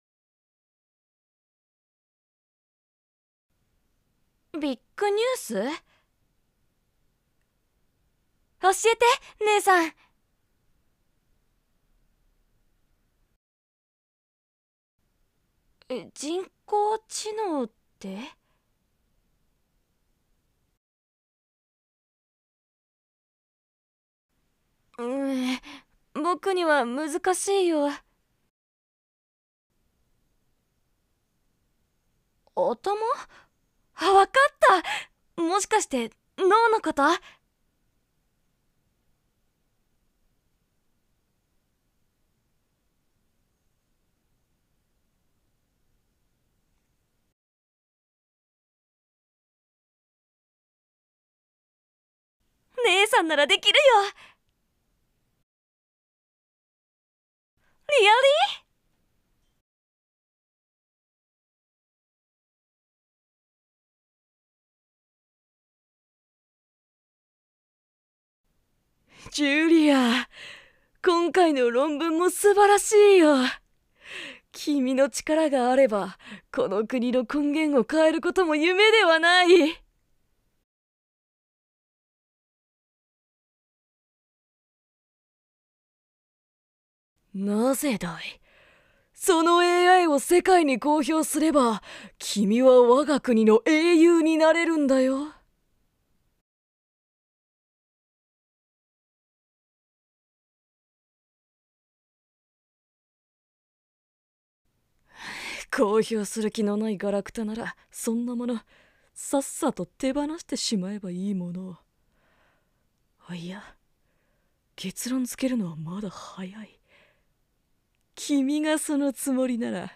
bgm抜き〈第8話〉望まぬ犠牲【前編】